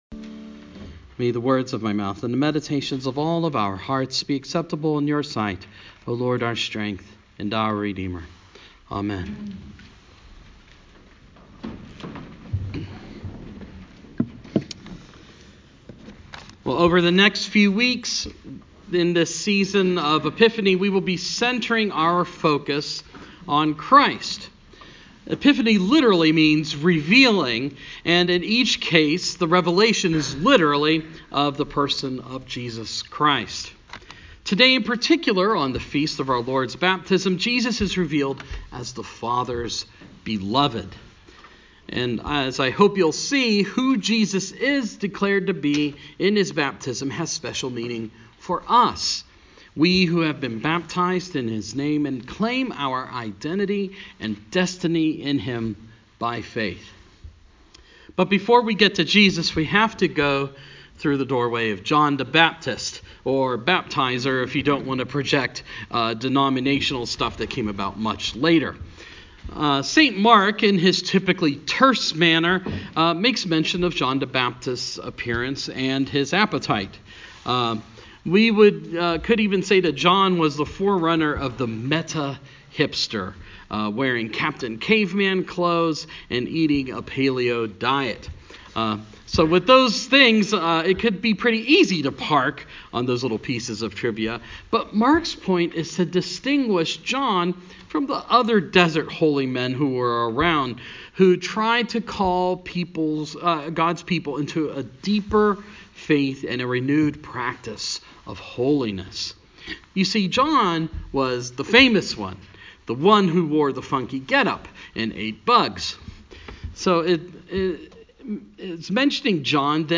Sermon – Baptism of Christ